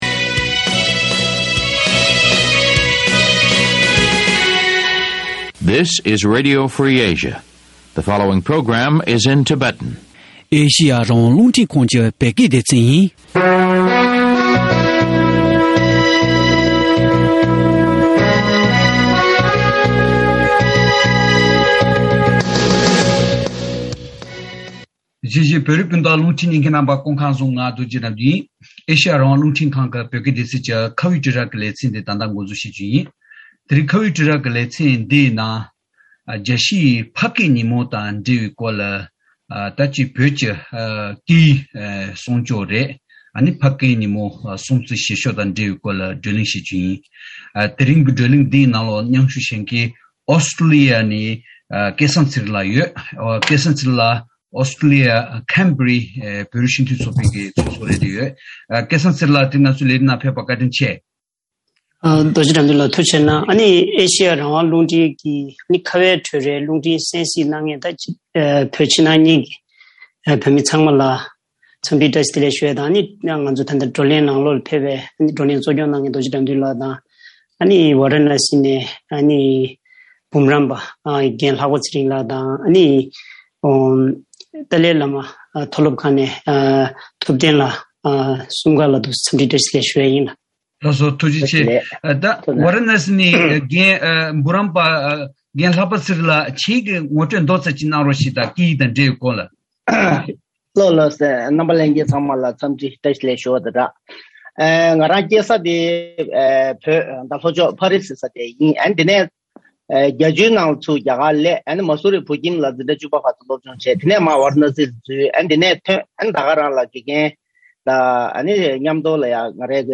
བོད་ཀྱི་སྐད་ཡིག་མི་ཉམས་རྒྱུན་འཛིན་བྱེད་ཕྱོགས་ཀྱི་ཐབས་ལམ་དང་གདོང་ལེན་གནང་དགོས་པའི་གནད་དོན། མ་འོངས་པའི་ཕུག་བསམ་སོགས་ཀྱི་སྐོར་བགྲོ་གླེང་།